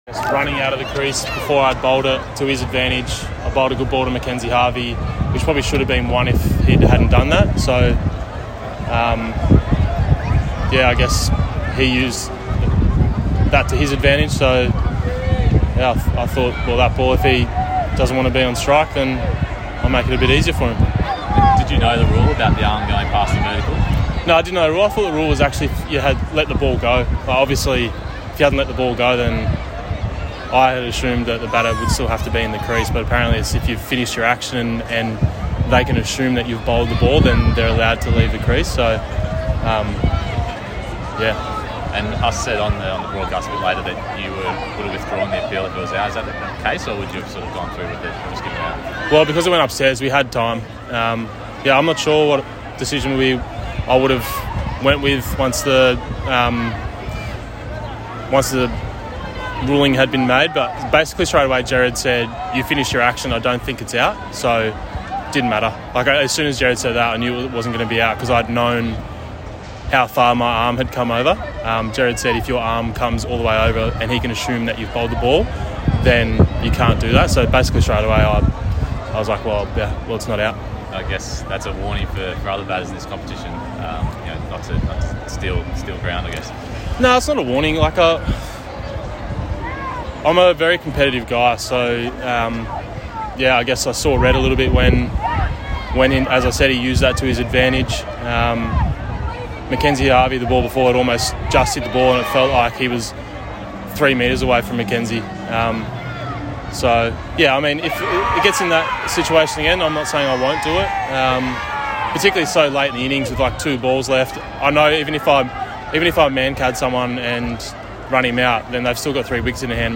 Adam Zampa Speaks Following Stars Loss to Renegades